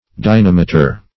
Dynameter \Dy*nam"e*ter\, n. [Gr.